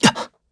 Siegfried-Vox_Damage_kr_01_b.wav